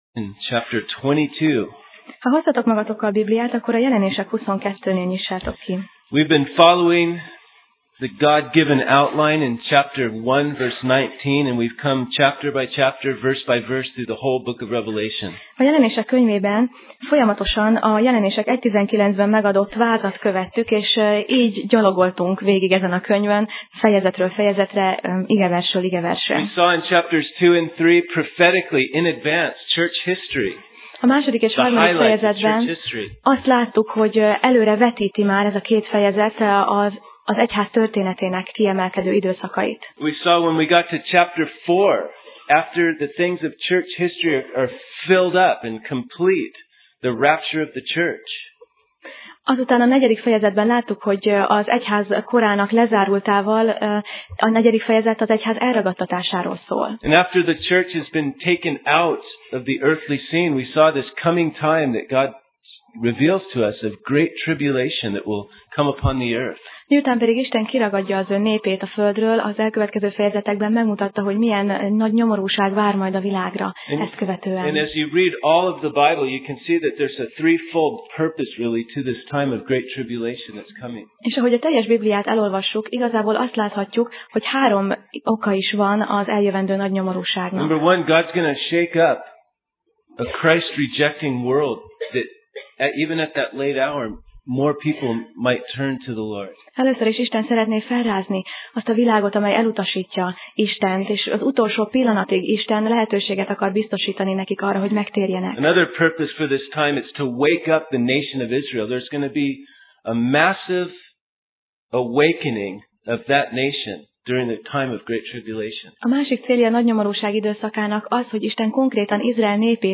Jelenések Passage: Jelenések (Revelation) 22 Alkalom: Vasárnap Reggel